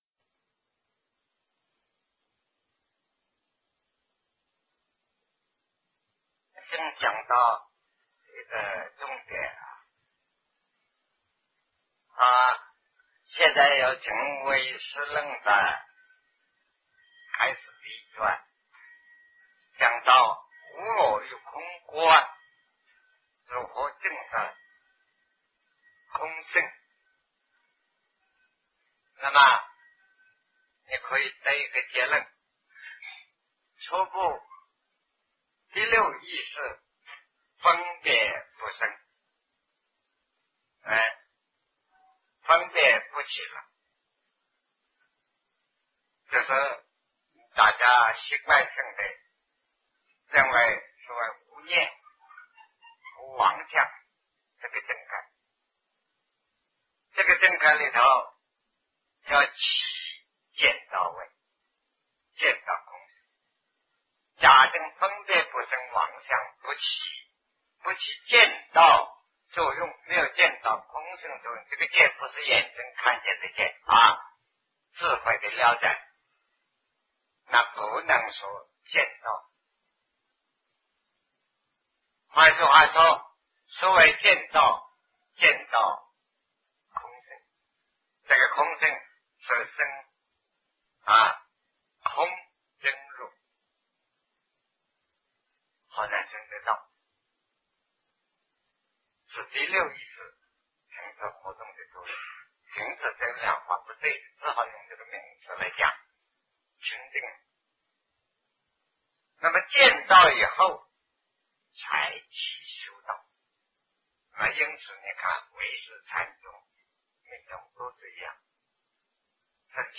一切我执皆缘五蕴 南师讲唯识与中观（1980代初于台湾054(上)